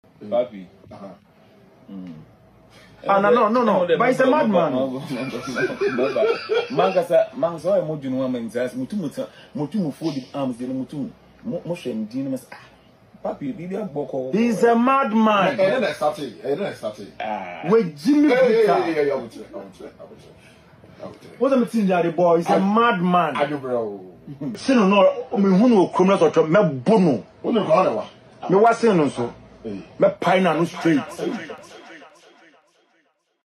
Ghana Music Music
Sensational Ghanaian rapper